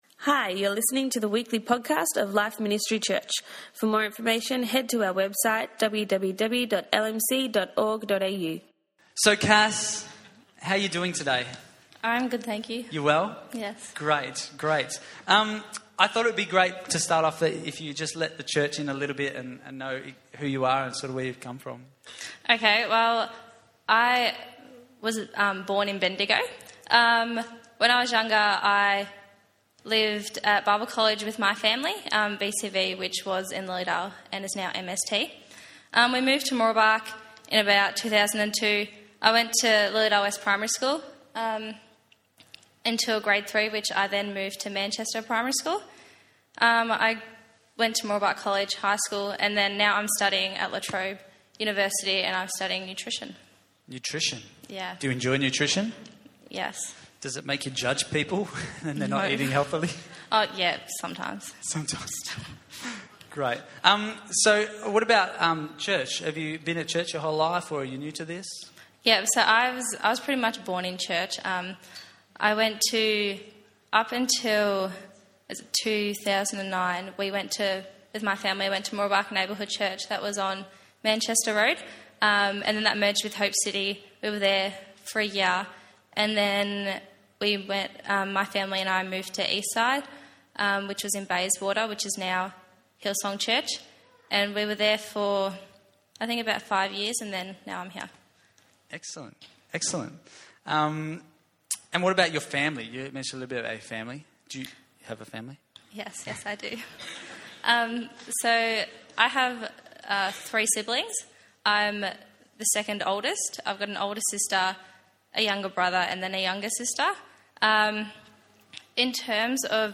Youth Service